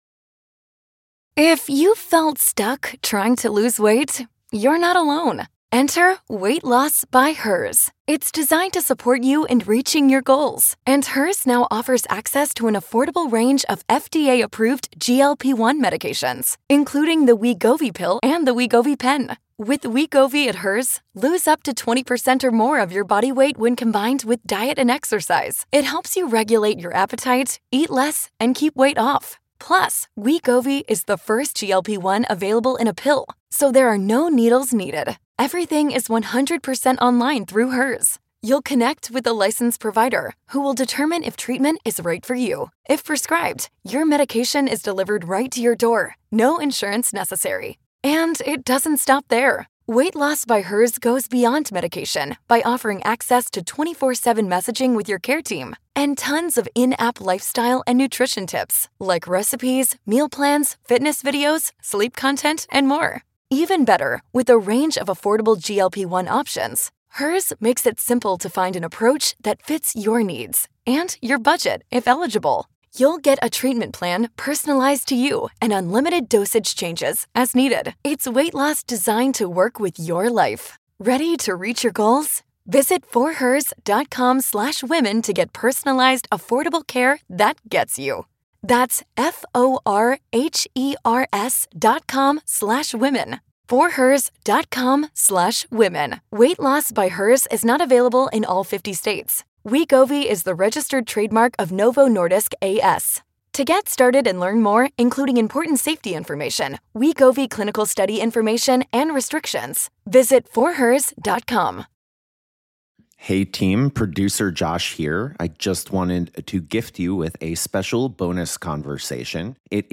In this far ranging conversation, they discuss why “leveling down” might be the best strategy for navigating late stage capitalism and bringing ourselves back into right relationship with each other and the planet.